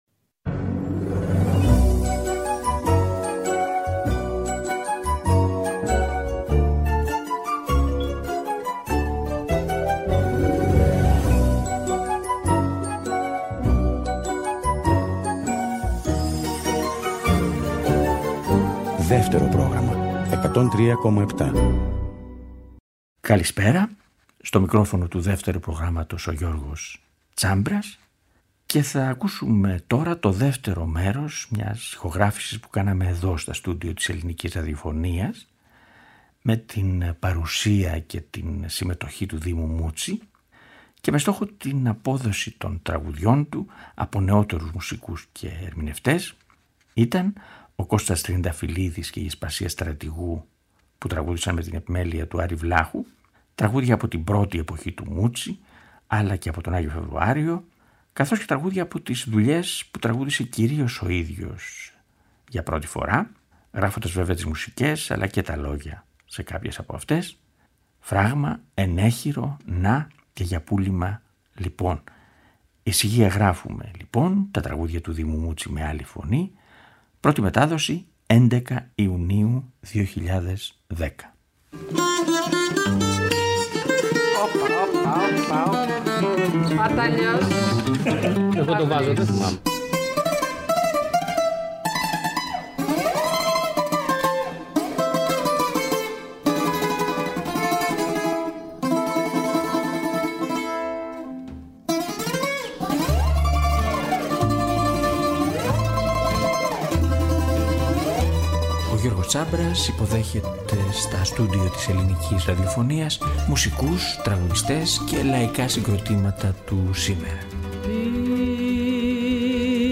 Μια ζωντανή ηχογράφηση του 2010, με τον Δήμο Μούτση… για τον Δήμο Μούτση
Παρ’ όλα αυτά, βρέθηκε μαζί μας στα στούντιο της Ελληνικής Ραδιοφωνίας, στα πλαίσια των ηχογραφήσεων της εκπομπής «Ησυχία! Γράφουμε…».